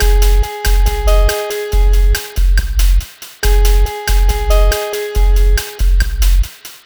Cheese Lik 140-G#.wav